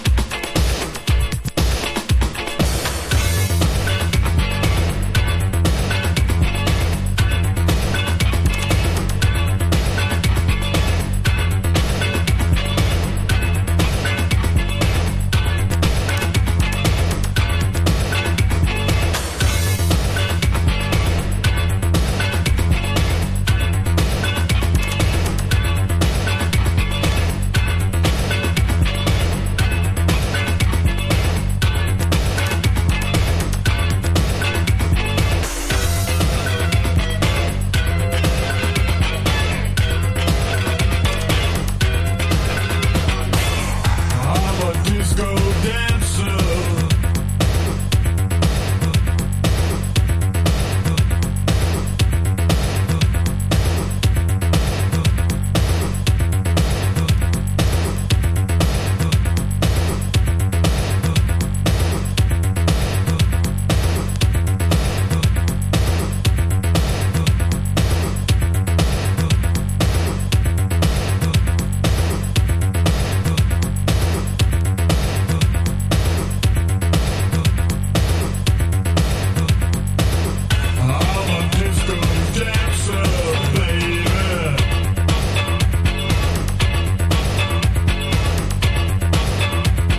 パワフルなダンスビートにチープなシンセベース、小気味良いカッティングが映えるNWディスコ。
# NU-DISCO / RE-EDIT